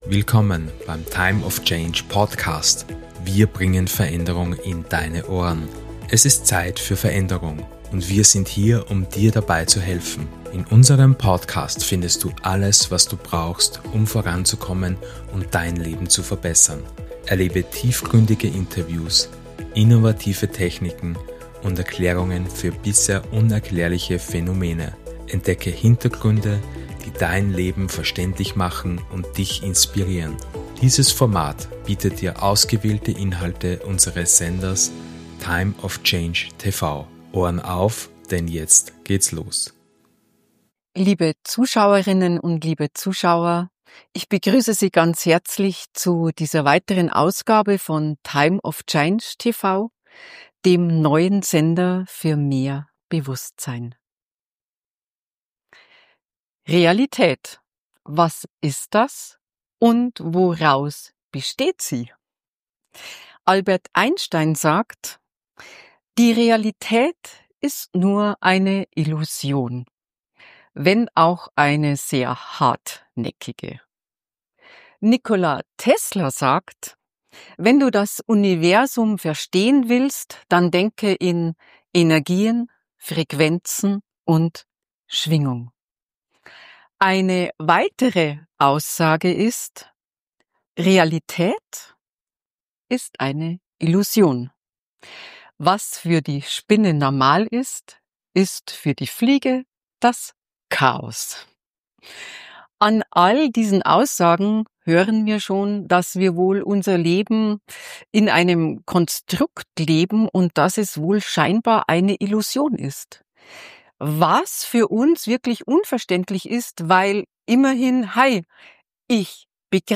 Dieses Interview wird Deine Sichtweise auf die Welt verändern und Dir neue Wege aufzeigen, wie Du Dein Leben selbstbestimmt gestalten kannst.